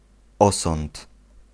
Ääntäminen
NL: IPA: /ˈœy̯tˌspraːk/ IPA: /ˈʌy̯tˌspraːk/